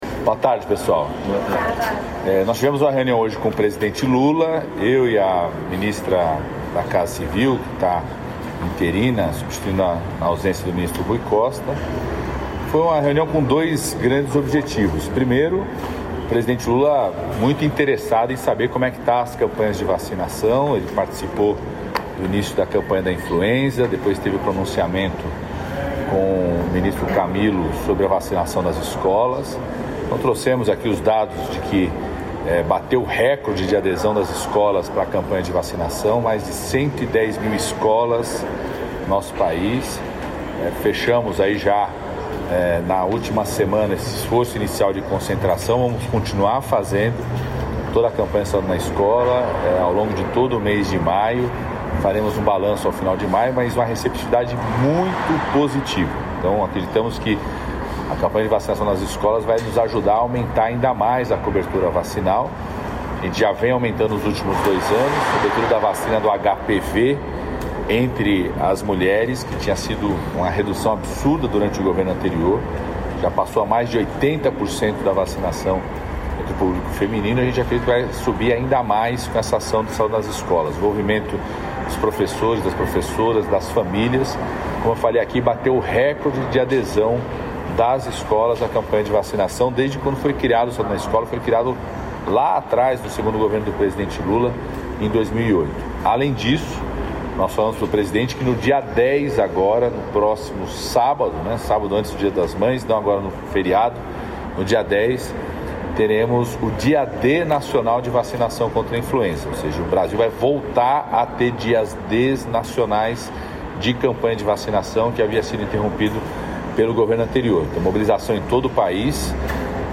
Íntegra da declaração dos ministros, Mauro Vieira, das Relações Exteriores e Marina Silva, do Meio Ambiente e Mudança do Clima, após reunião virtual de alto nível com a participação do presidente Luiz Inácio Lula da Silva e mais 19 chefes de estado mundiais, nesta quara-feira (23), em Brasília.